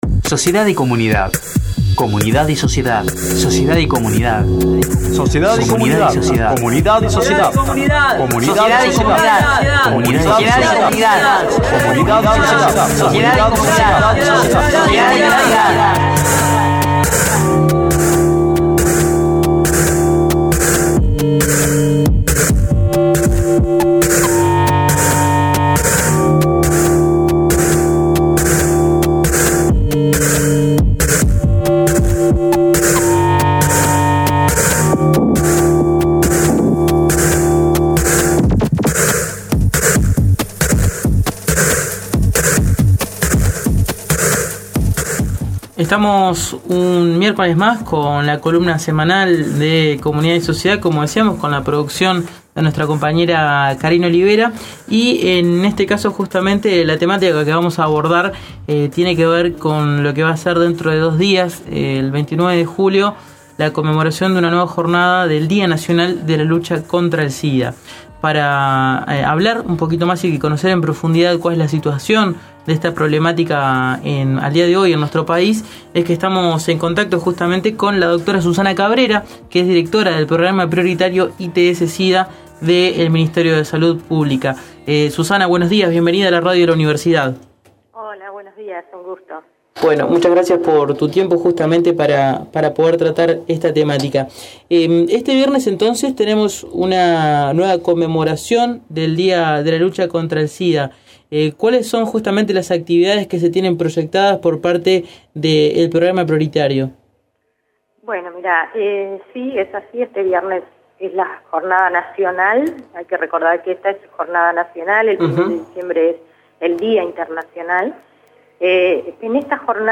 Entrevista
entrevistamos telefónicamente